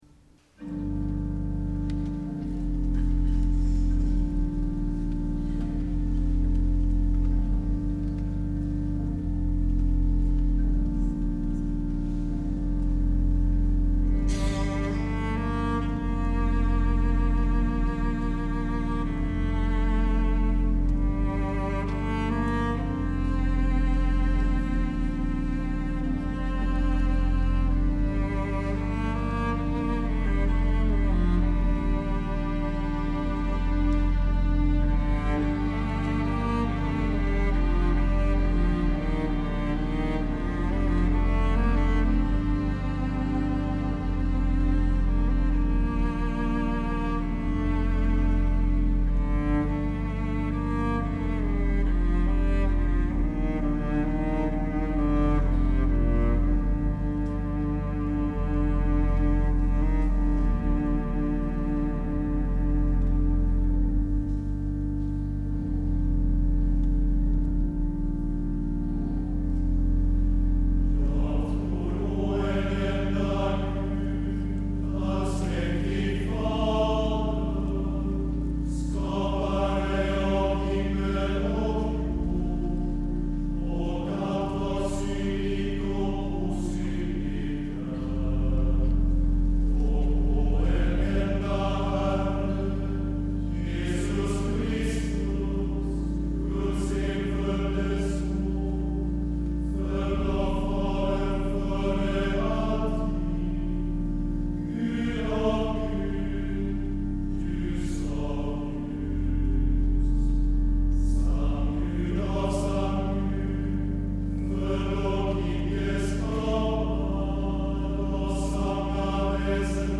Orkester: Stråkorkester, Blåsarkvintett, 3 slagverk, Orgel